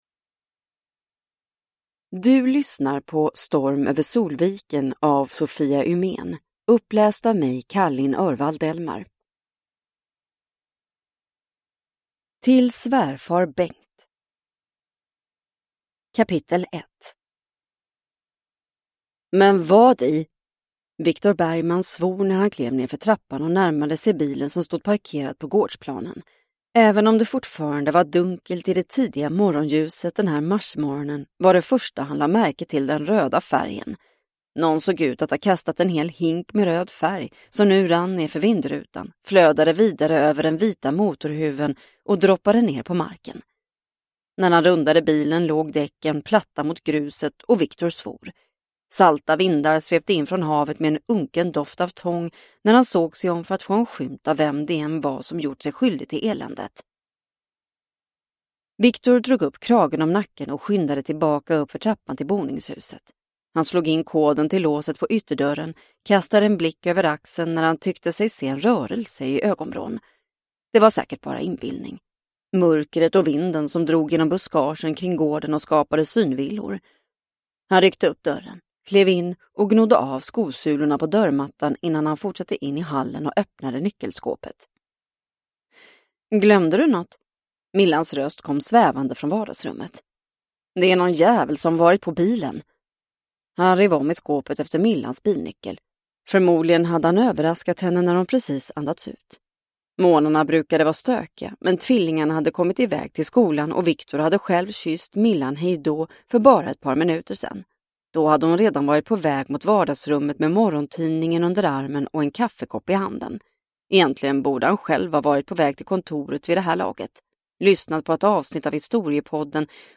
Storm över Solviken – Ljudbok – Laddas ner